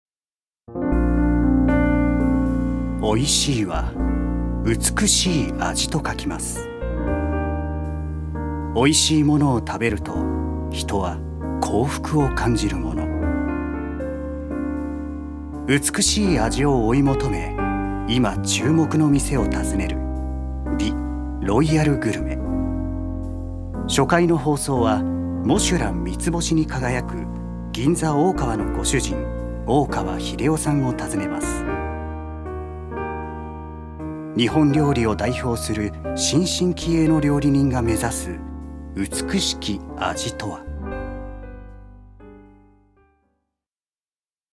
所属：男性タレント
ナレーション２